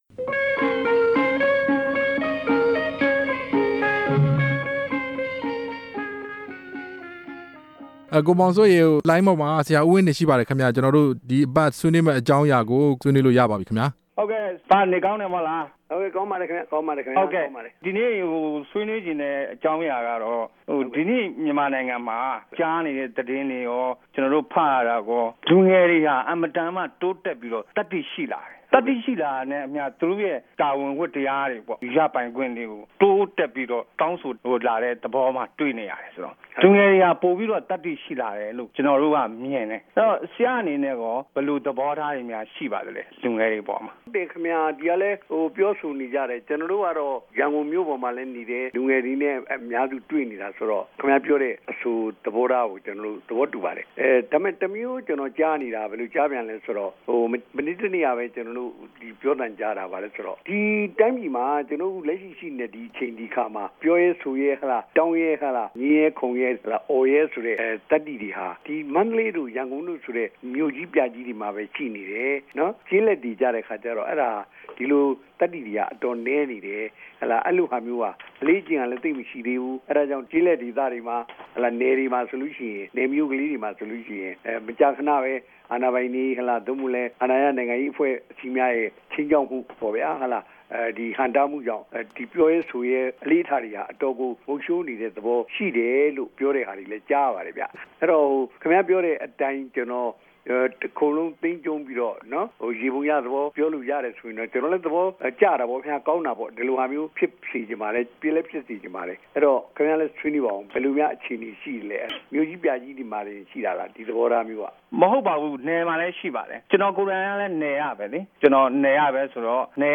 ဦးဝင်းတင်နှင့် စကားပြောခြင်း